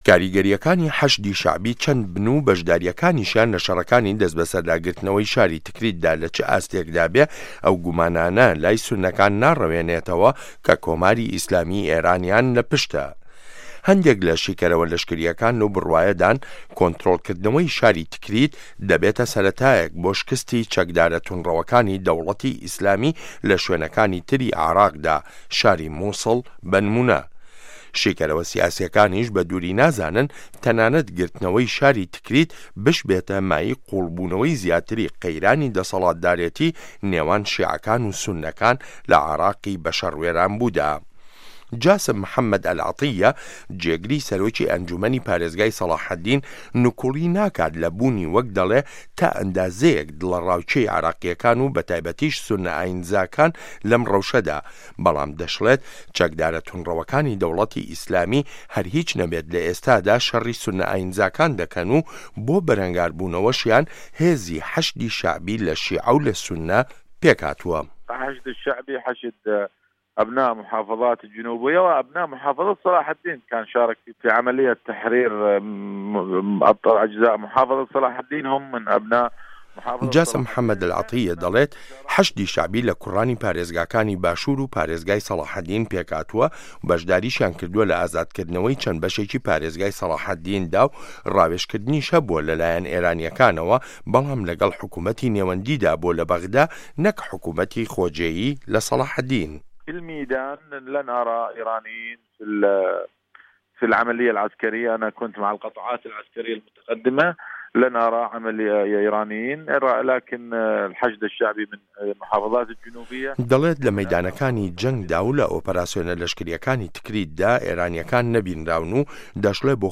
ڕاپۆرت